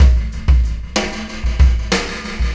Pulsar Beat 20.wav